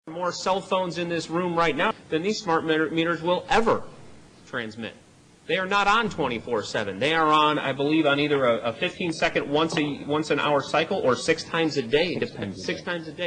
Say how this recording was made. In the City of Naperville, Illinois, concerned citizens at a City Council meeting were told that smart meters are only on “six times a day.” (Reference: Audio link below for Naperville City Council meeting, February 15, 2011)